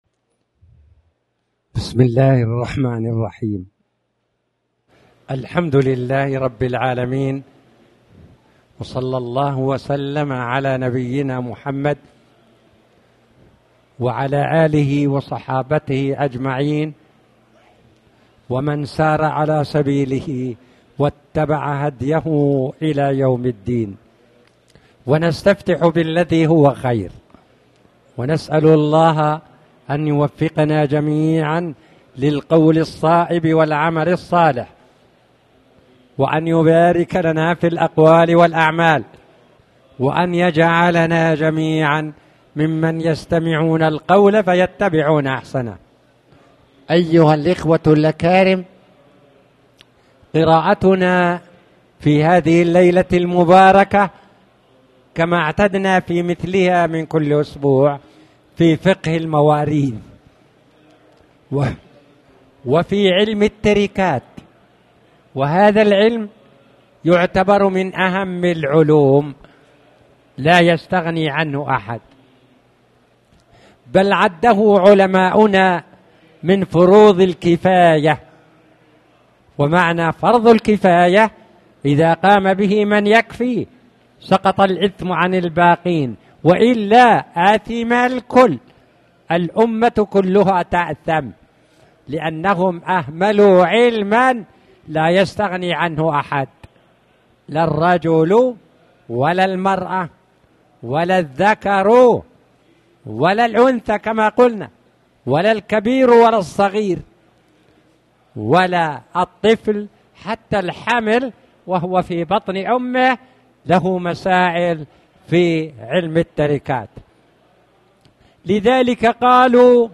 تاريخ النشر ١٧ صفر ١٤٣٩ هـ المكان: المسجد الحرام الشيخ